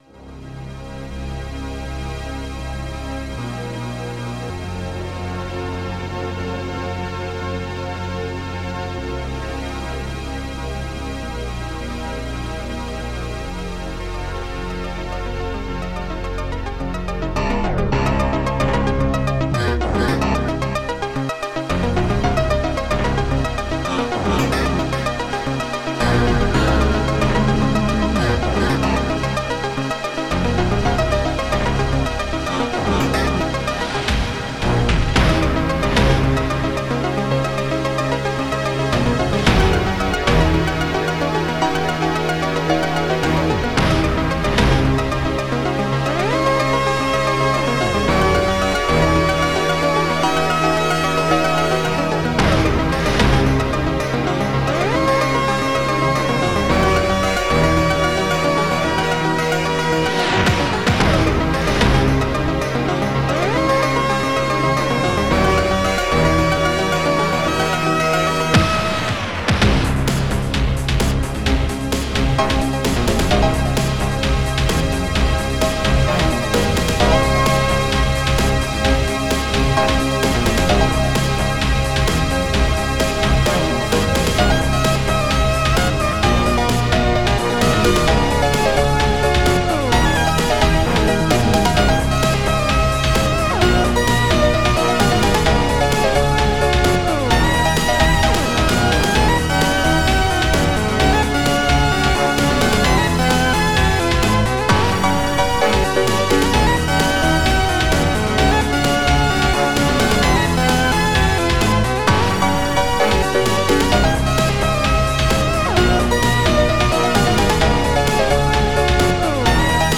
s3m (Scream Tracker 3)